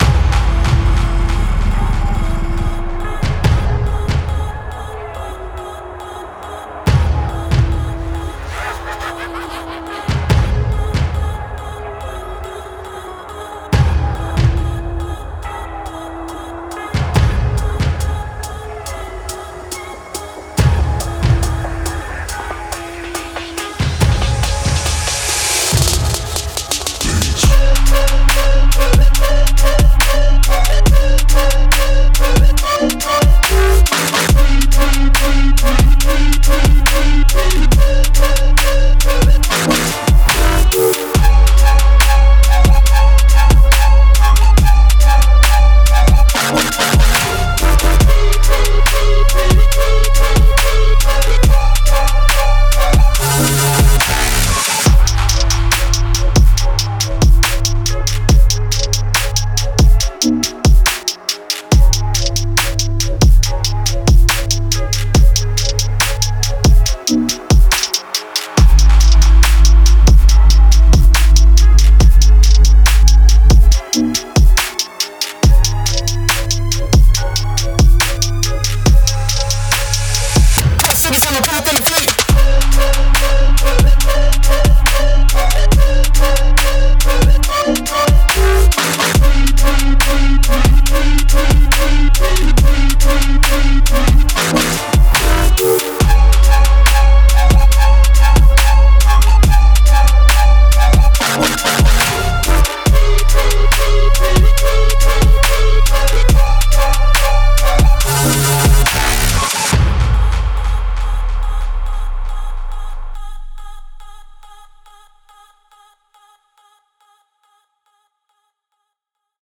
halftime and bass-driven sounds
dark, spacious vibe